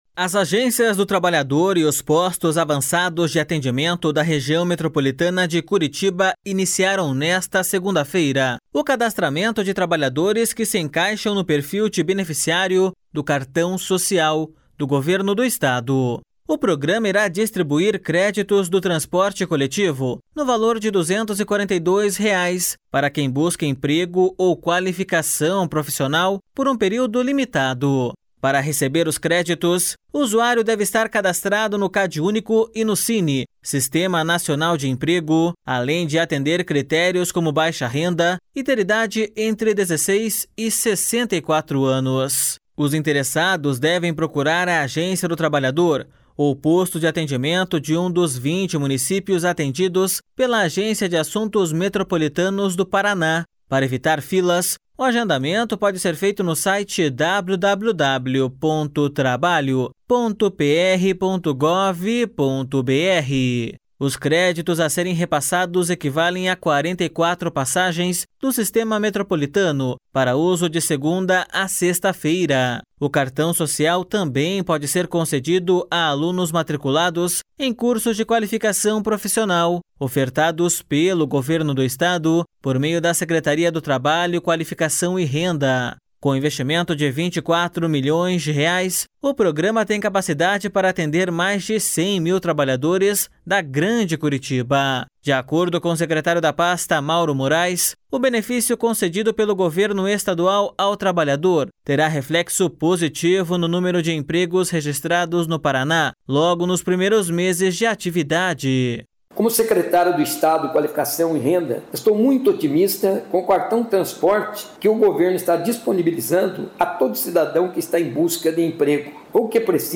De acordo com o secretário da pasta, Mauro Moraes, o benefício concedido pelo governo estadual ao trabalhador terá reflexo positivo no número de empregos registrados no Paraná logo nos primeiros meses de atividade.// SONORA MAURO MORAES.//